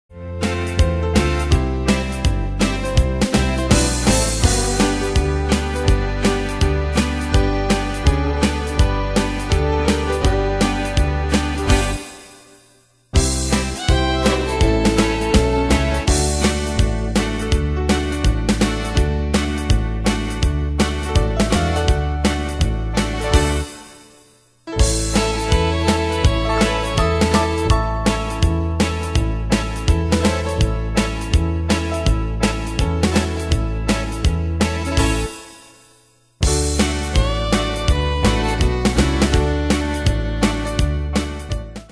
Style: Country Année/Year: 2002 Tempo: 165 Durée/Time: 3.05
Pro Backing Tracks